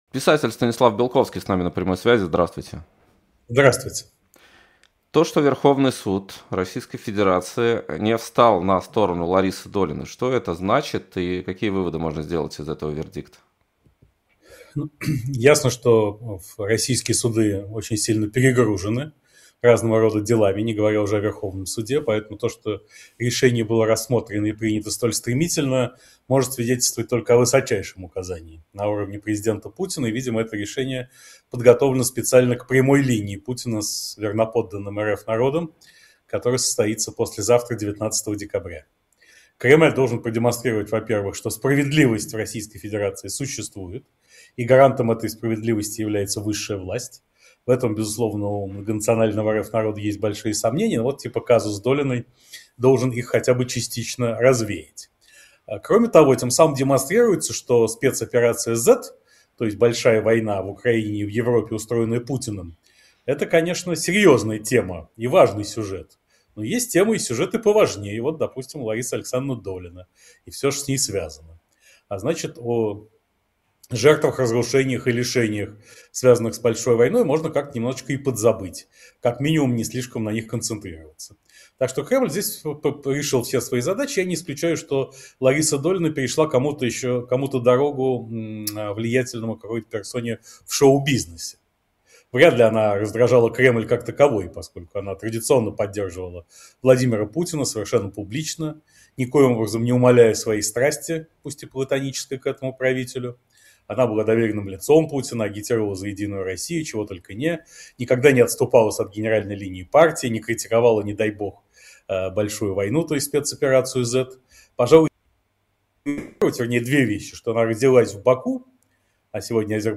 Эфир ведёт Вадим Радионов